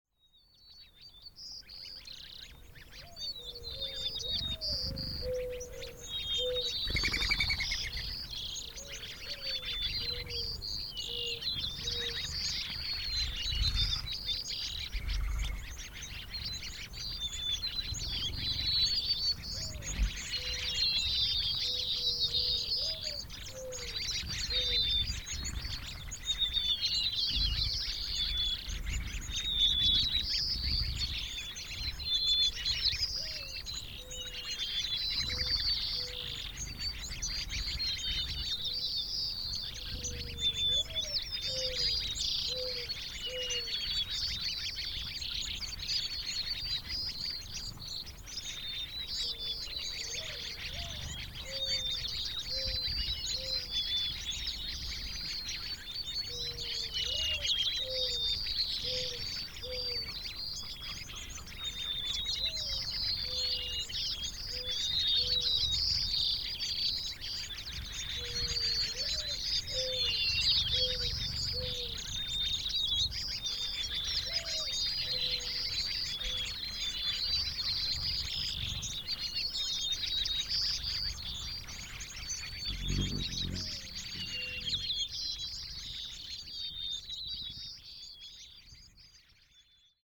Anvil Springs Dusk-Excerpt
DeathVlyOasis-AnvilDusk-Excerpt.mp3